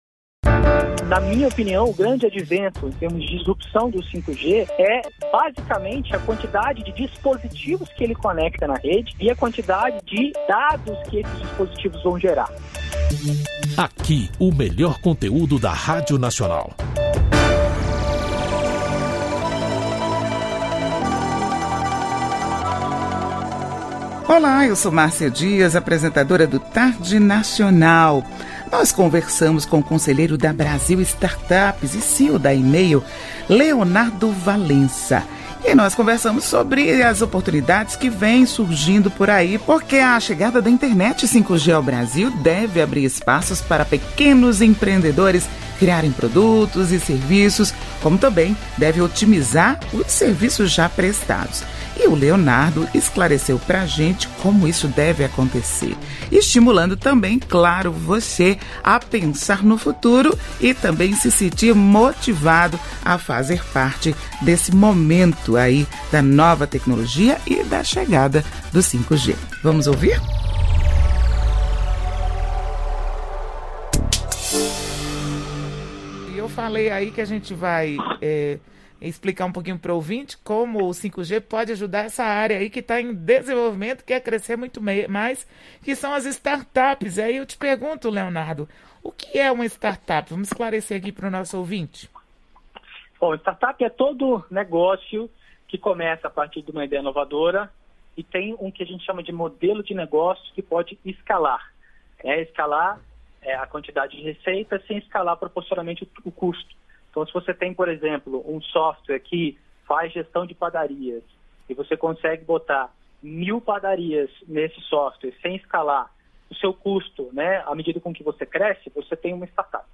Podcast Entrevista é Nacional: como o 5G pode ajudar as startups